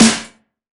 SNARE 047.wav